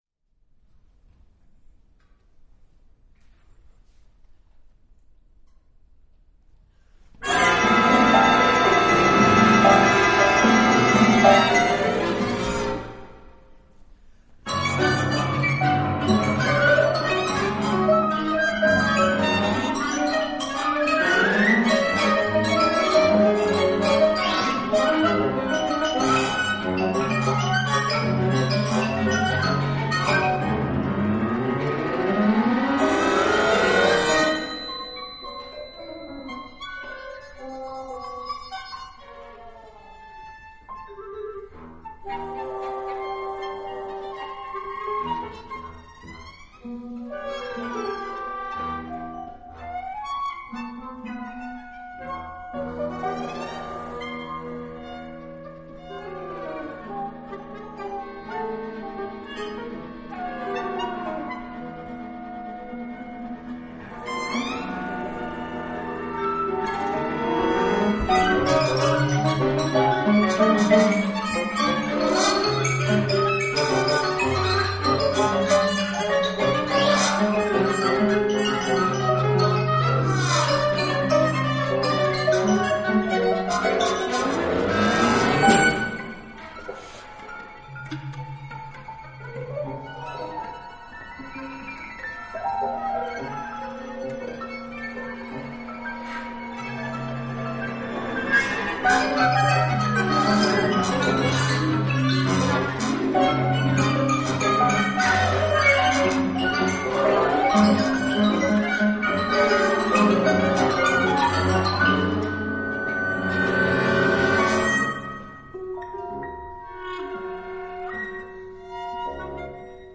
Konzerthaus, Berlin, Germany